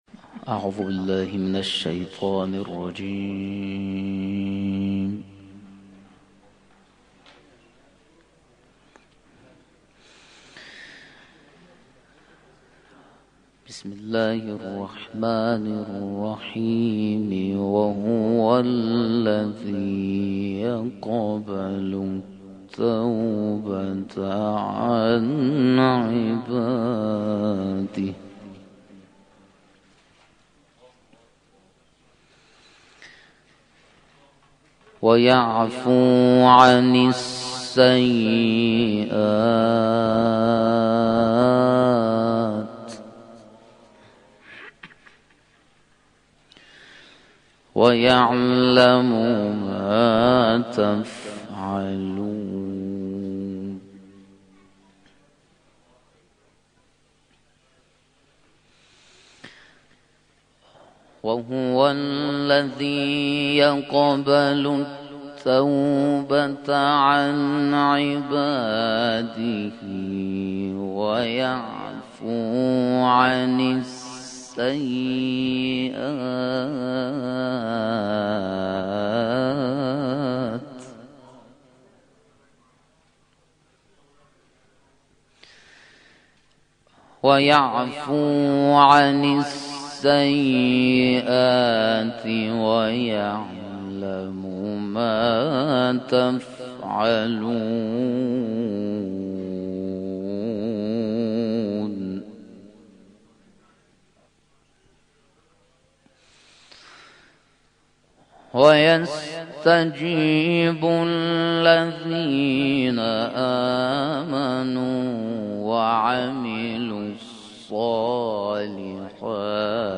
تلاوت
در طولانی ترین محفل انس با قرآن